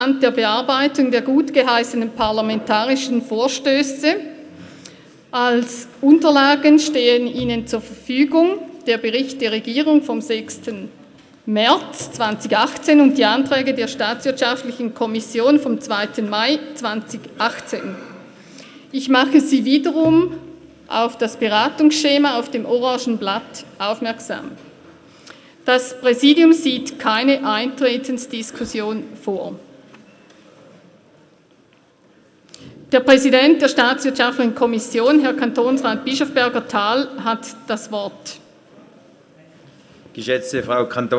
Session des Kantonsrates vom 11. bis 13. Juni 2018